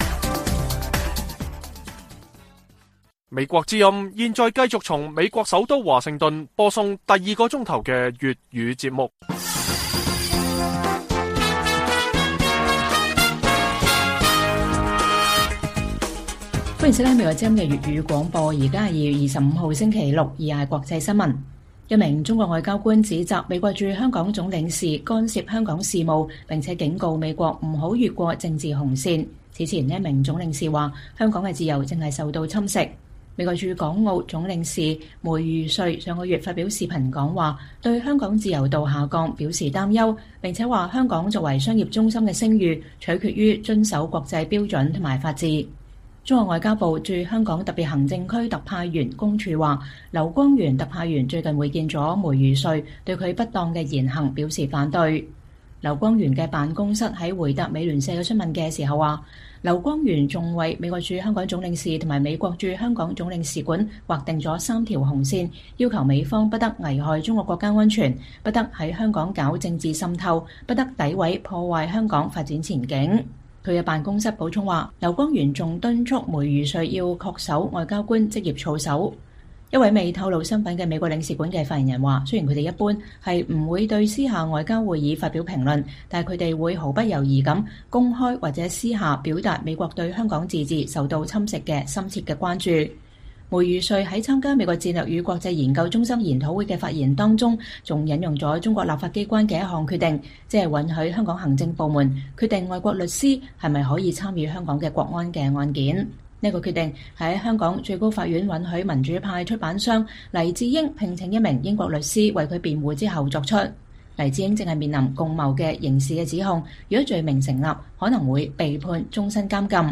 粵語新聞 晚上10-11點：不滿美國駐港官員批評香港的自由和法治 中國霸道劃出“三條紅線”